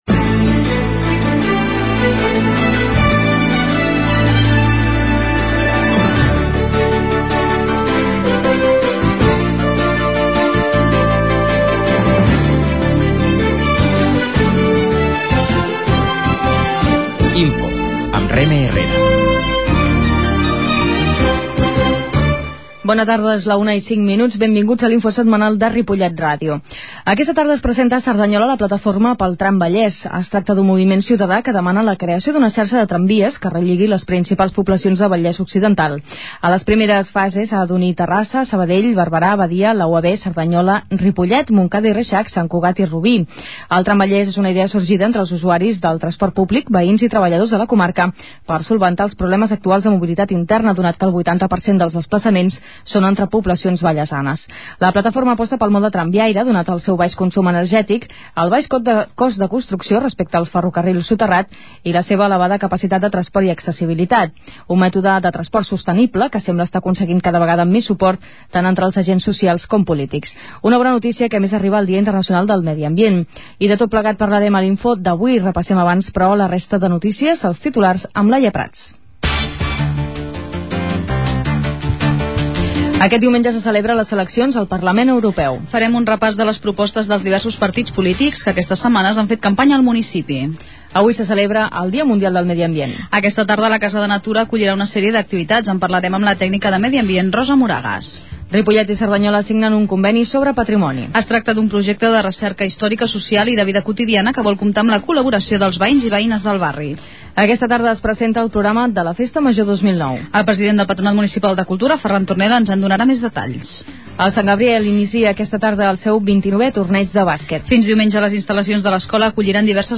Aquesta setmana ens ha acompanyat a l'INFO el segon tinent d'alcalde, Xavier Pe�arando.
La qualitat de so ha estat redu�da per tal d'agilitzar la seva desc�rrega.